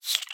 mob / silverfish / say3.ogg